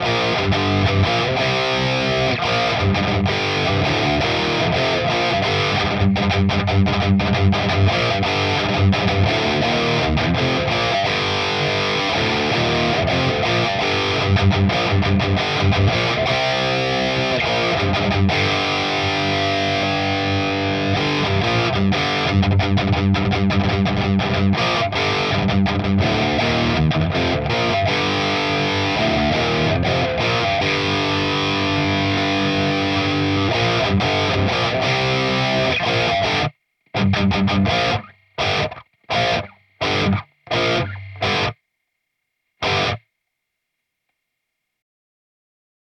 Sorry für das sloppy Spiel. In der Eile einfach mal kurz ein paar Riffs zum Vergleich reingekloppt. Die ersten sind mit One, dann ohne und die letzen Riffs sind nochmal mit. Gerade an dem Galopps merkt man den TS. Räumt auf, macht tight und komprimiert ein bisschen.
Edit: Als Profil hab ich das selbst erstellte TS9DX Capture genommen und in mein JMP-1 Capture auf dem Pedal gespielt.